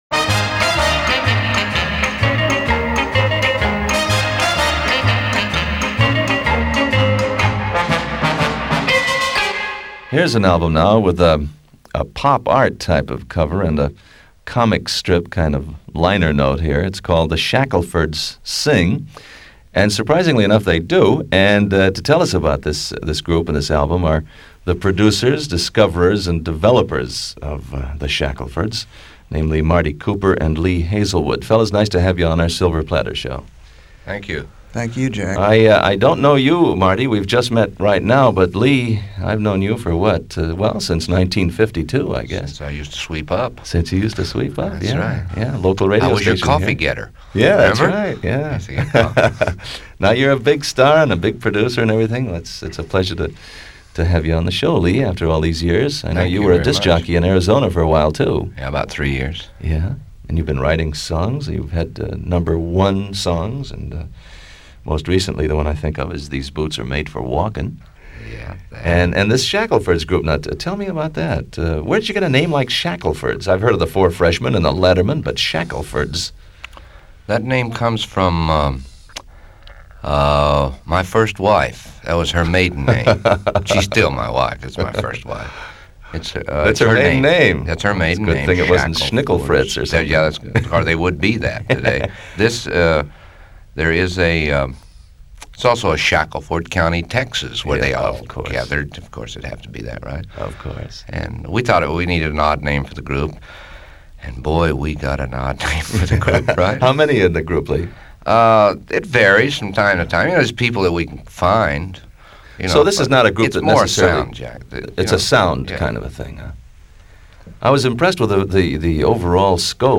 In the 1960's Capitol Records pressed promotional albums for radio station play called The Silver Platter Service.